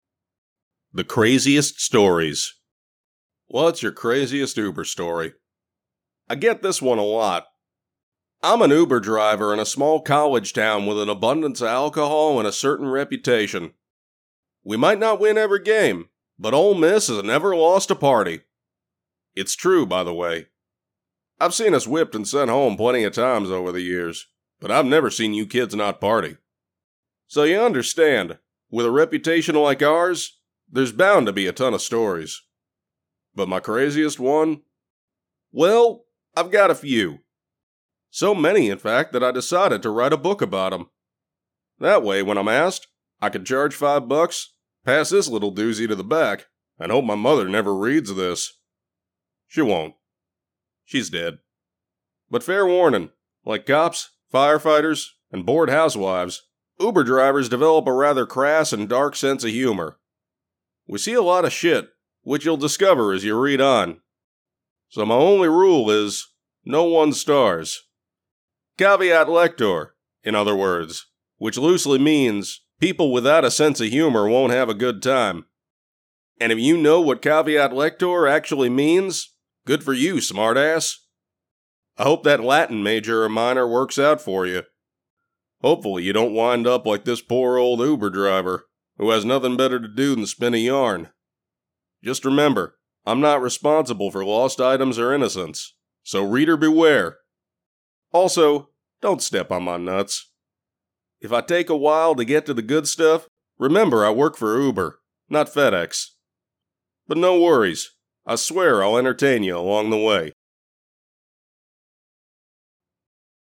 When I talk, people listen, and I can lend that gravitas to your story with a rich and clear sound.
Audiobook Sample 1